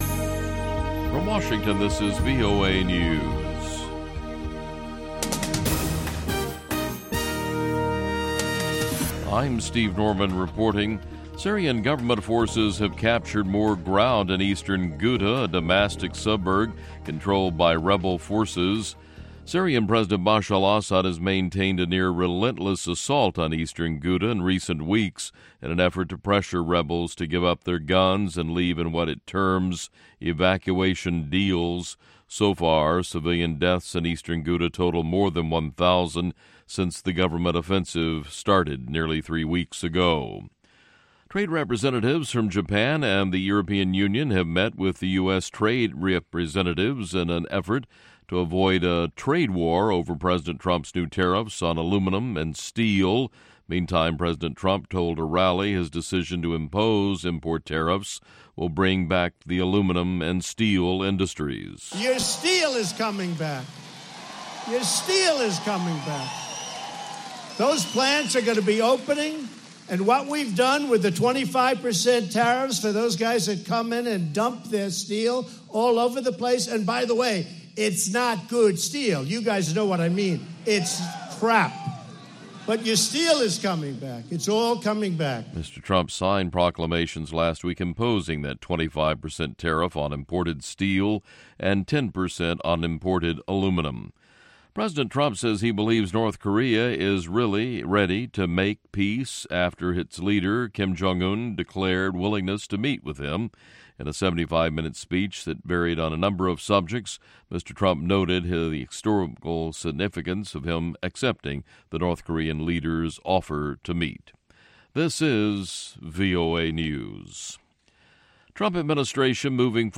Tuku Live from Zimbabwe - Music Time in Africa